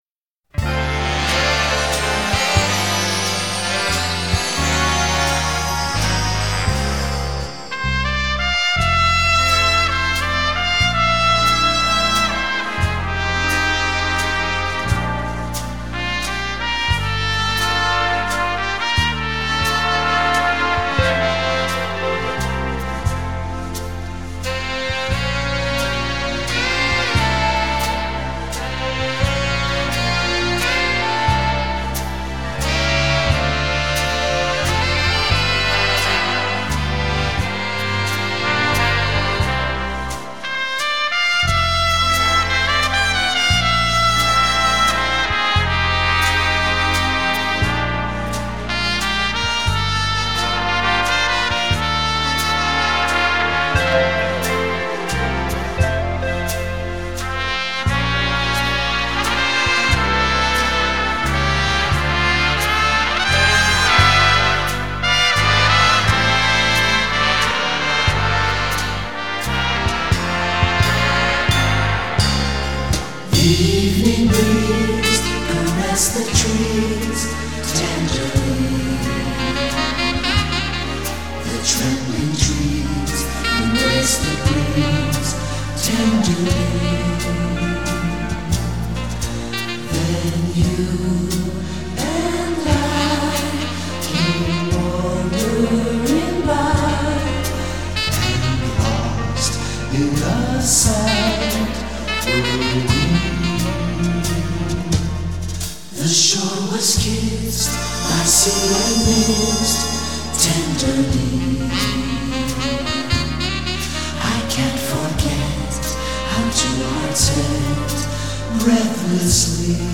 Valse Lente